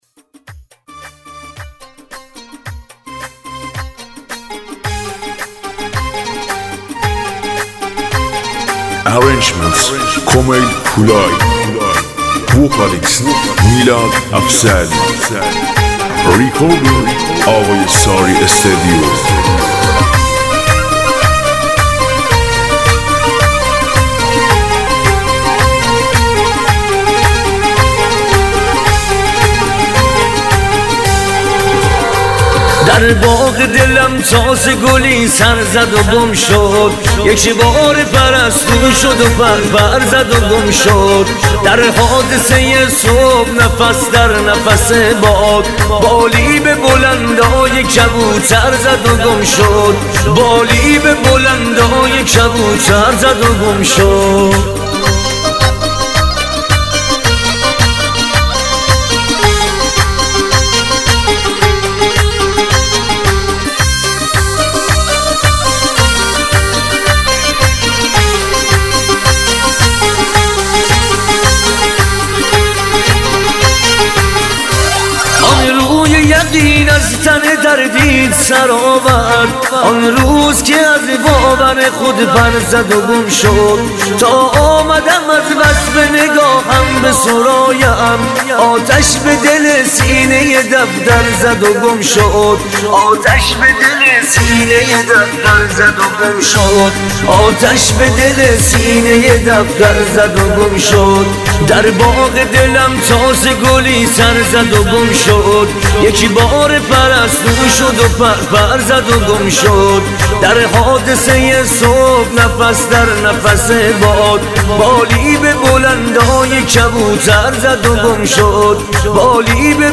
ریمیکس اول